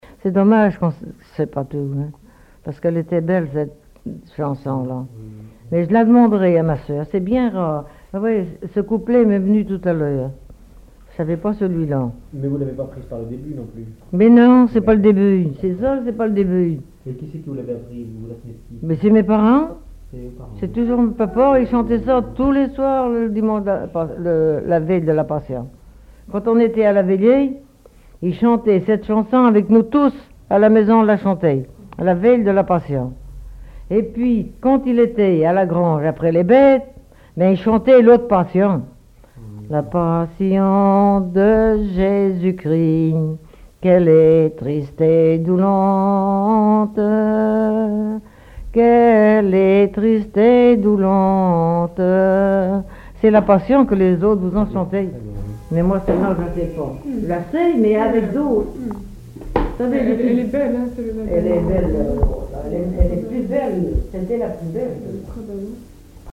collecte en Vendée
Témoignages et chansons traditionnelles
Catégorie Témoignage